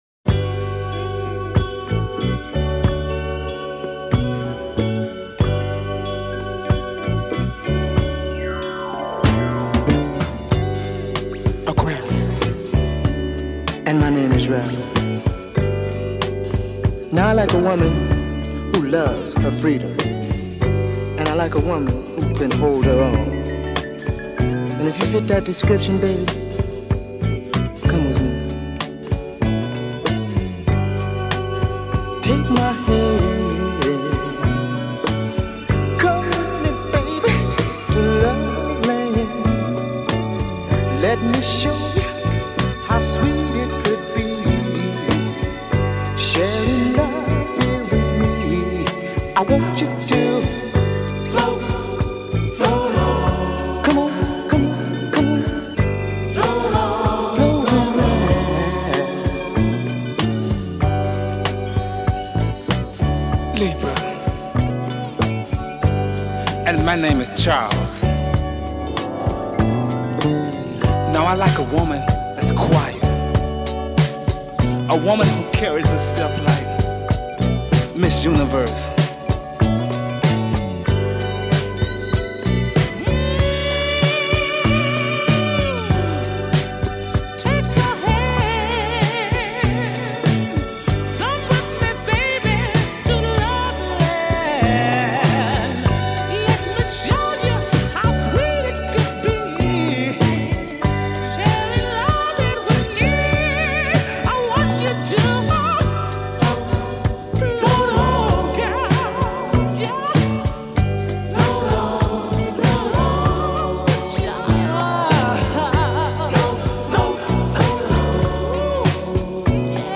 minimalist version